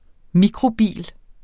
Udtale [ ˈmikʁo- ]